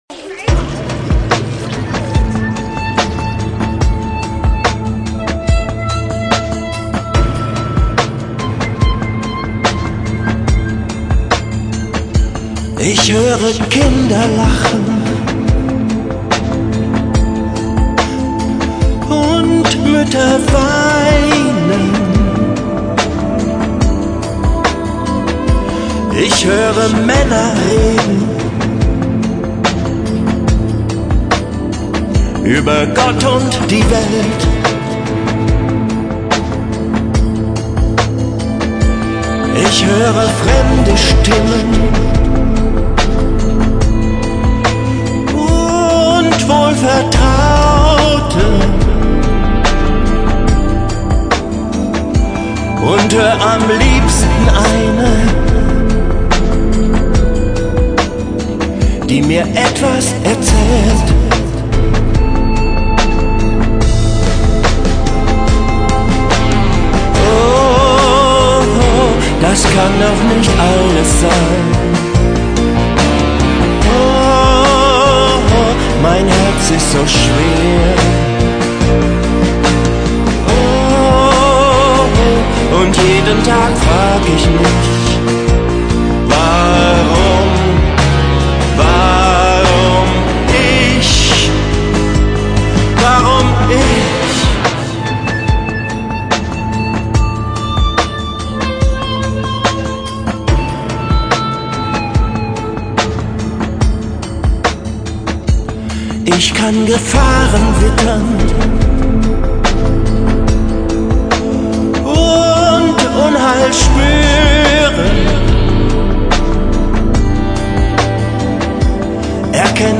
Am Samstag den 5.5. wurde in der Bernstädter Kirche das Kindermusical „Die Heilung des Blinden Bartimäus“ aufgeführt.
Flöte & Gitarre
Keyboard
Cajón